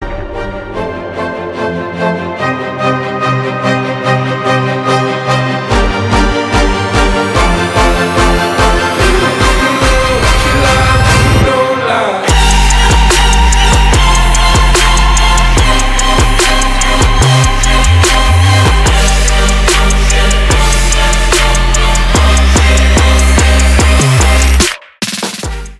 громкие
нарастающие
Trap
эпичные